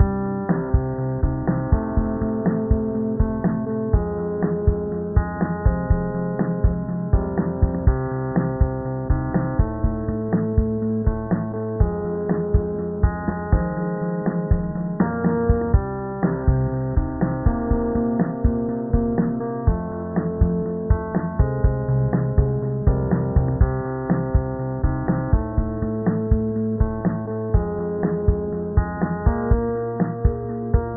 numerique-4410Hz.wav